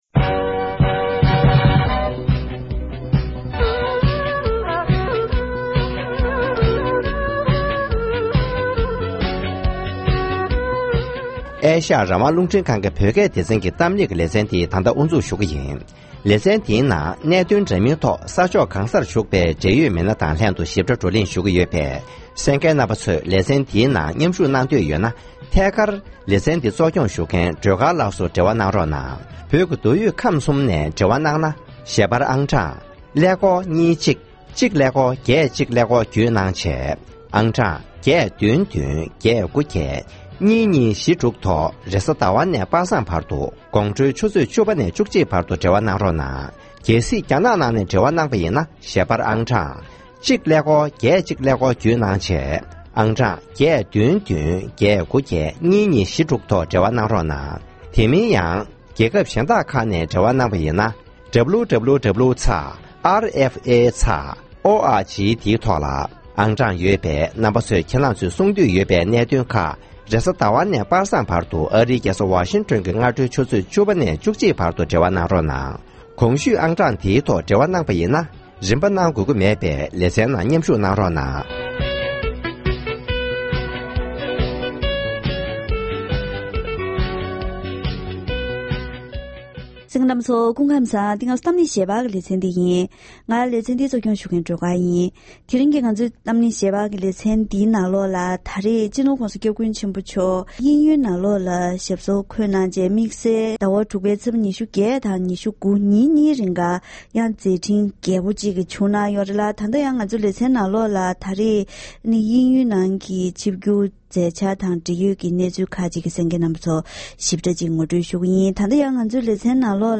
ཐེངས་འདིའི་གཏམ་གླེང་ཞལ་པར་ལེ་ཚན་ནང་སྤྱི་ནོར་༧གོང་ས་སྐྱབས་མགོན་ཆེན་པོ་མཆོག་གི་དབྱིན་ཡུལ་དུ་ཉིན་གཉིས་མཛད་འཕྲིན་རྒྱས་པོ་བྱུང་བའི་ཞིབ་ཕྲའི་གནས་ཚུལ་ཁག་གི་ཐད་དབྱིན་ཡུལ་སྐུ་ཚབ་དོན་གཅོད་ཁང་གི་འབྲེལ་ཡོད་མི་སྣ་དང་ལྷན་བཀའ་མོལ་ཞུས་པ་གསན་རོགས།